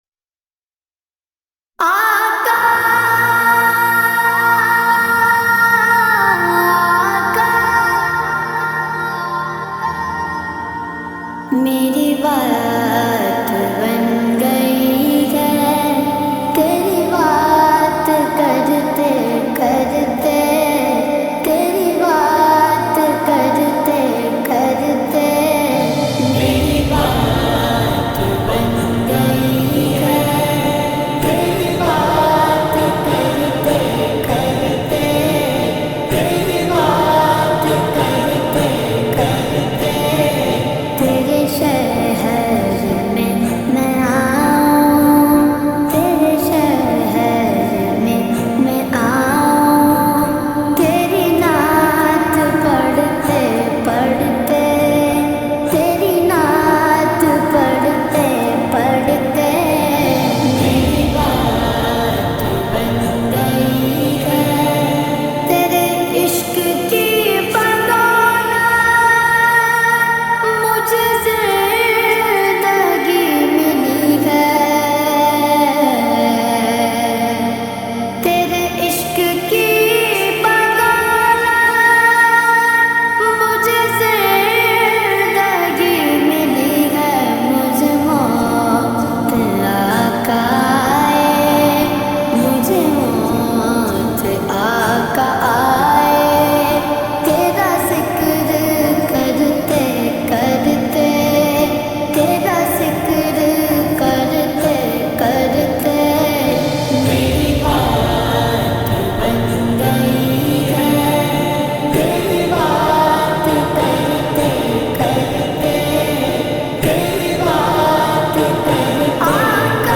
Heart Touching Naat